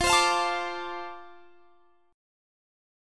F6 Chord
Listen to F6 strummed